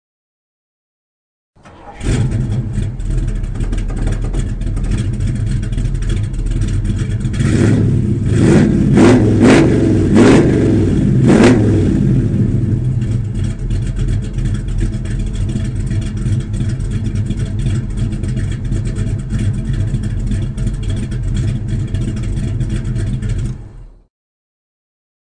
Кто тут хотел мускул-кар послушать?
Правда какчество оставляет желать лучшего...
1969_Chevrolet_.mp3